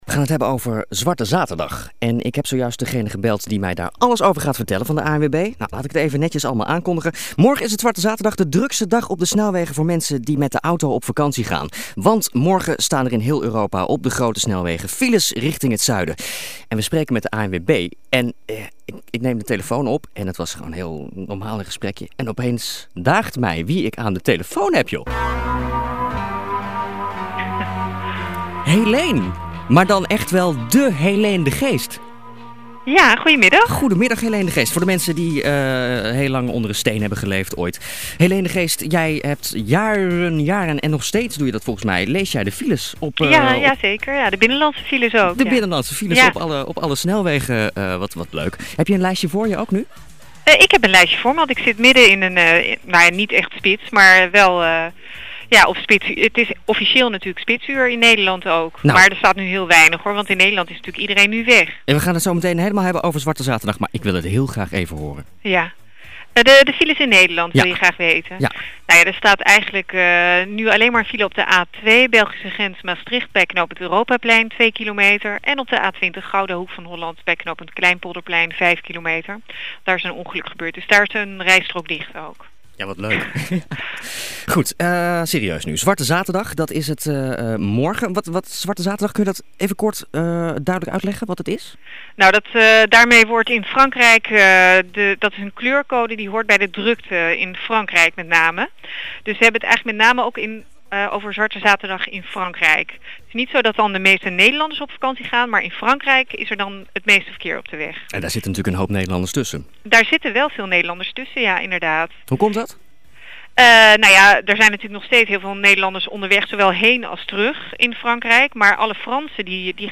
Ze gaf tips waar de meeste files staan en hoe je die  files kan ontwijken.